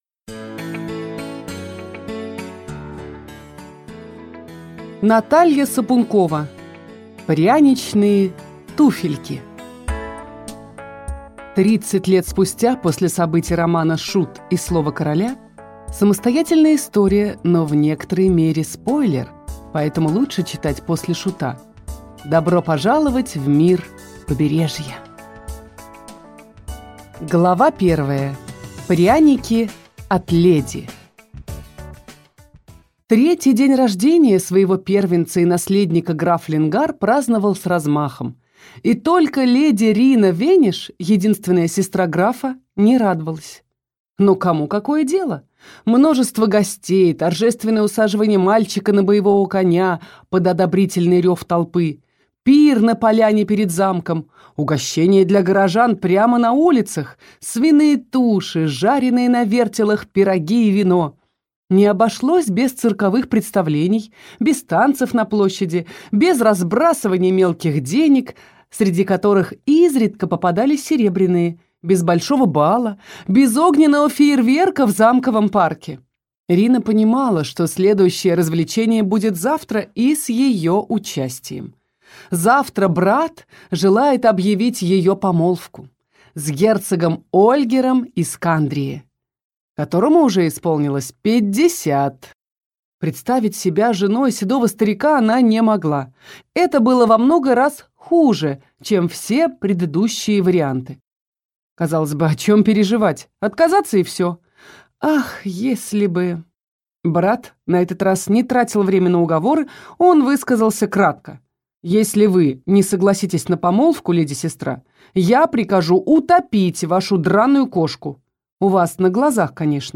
Аудиокнига Пряничные туфельки | Библиотека аудиокниг
Прослушать и бесплатно скачать фрагмент аудиокниги